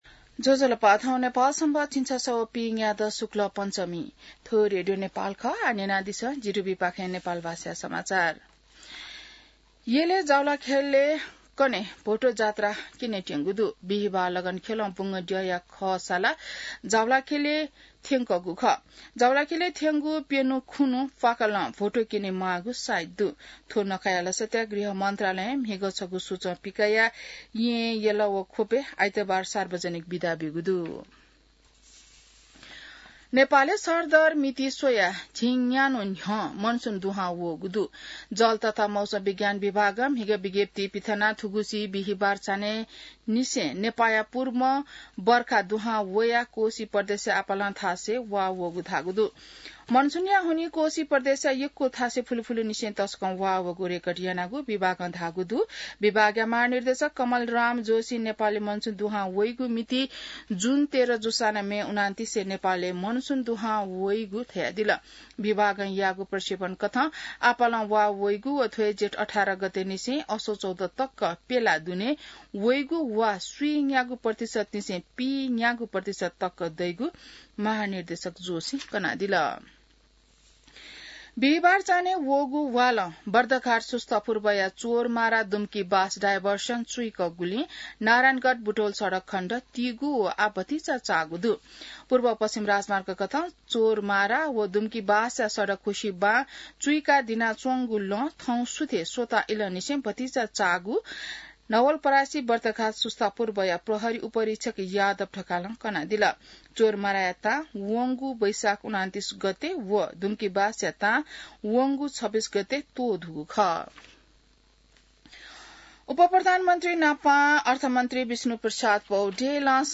नेपाल भाषामा समाचार : १७ जेठ , २०८२